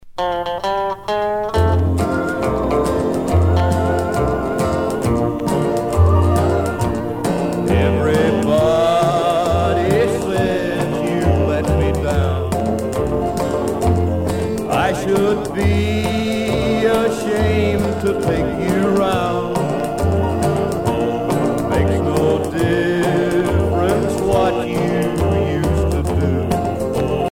danse : slow fox
Pièce musicale éditée